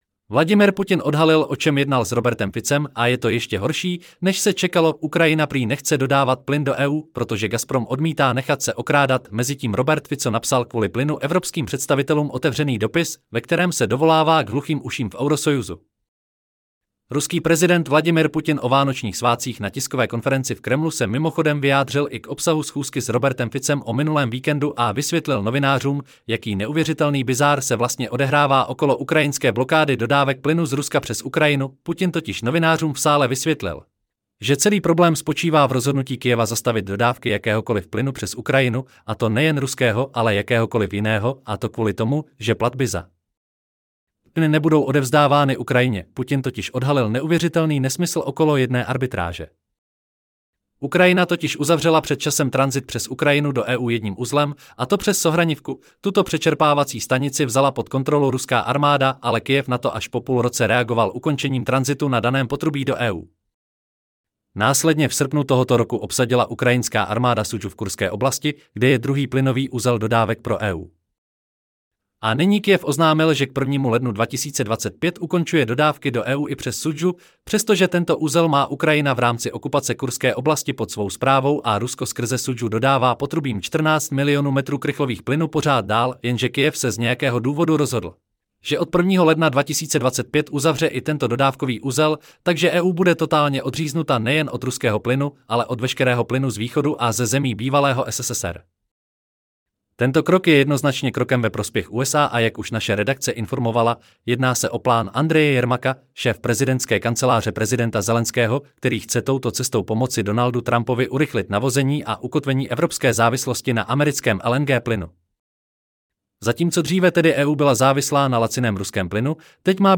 Celý článek si můžete poslechnout v audioverzi zde: Vladimir-Putin-odhalil-o-cem-jednal-s-Robertem-Ficem-a-je-to-jeste-horsi-nez-se-cekaloUkrajina 30.12.2024 Vladimir Putin odhalil, o čem jednal s Robertem Ficem, a je to ještě horší, než se čekalo!